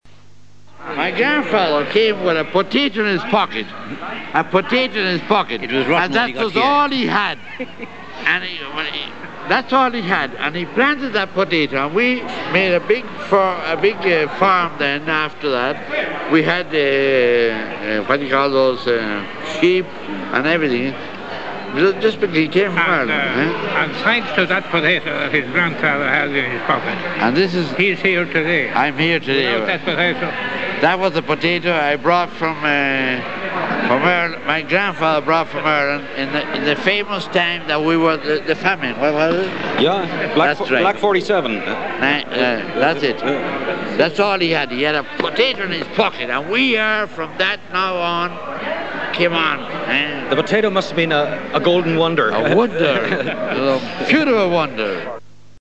Dinner at the Hurling Club of Buenos Aires, 25 May 1987,